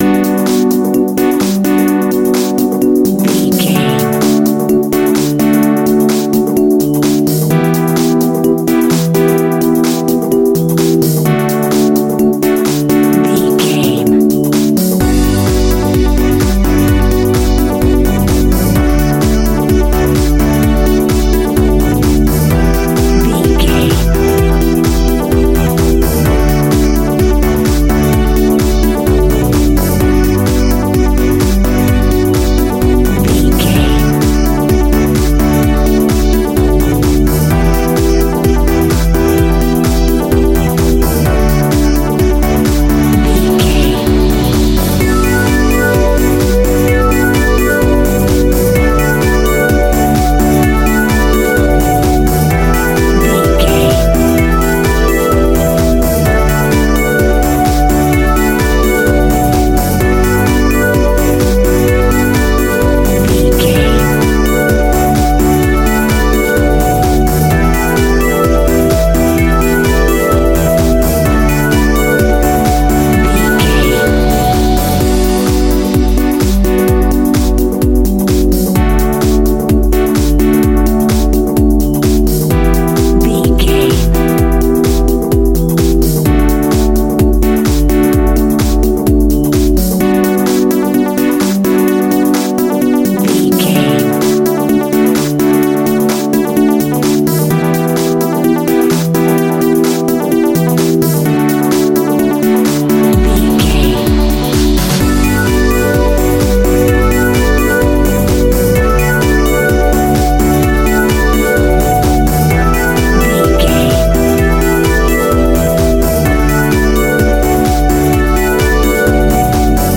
Ionian/Major
D
groovy
uplifting
energetic
drum machine
synthesiser
disco
instrumentals
funky guitar
wah clavinet
synth bass
horns